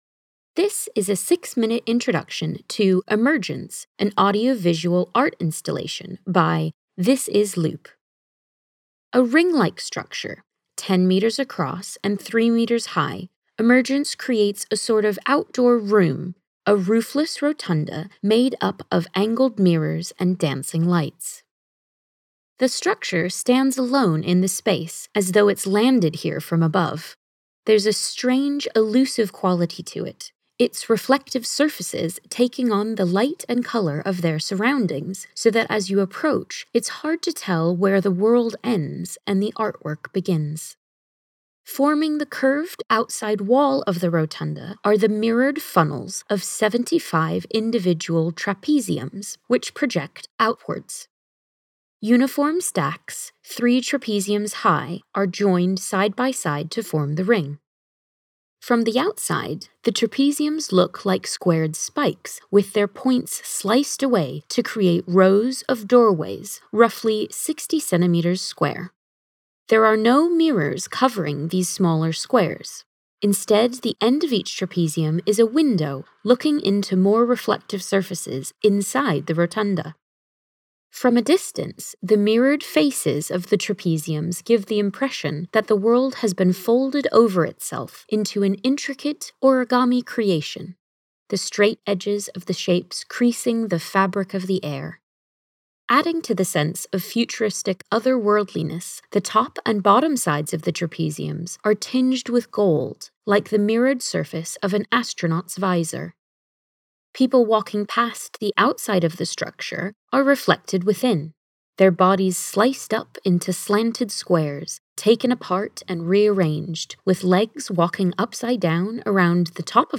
Audio Description
audio-description-emergence.mp3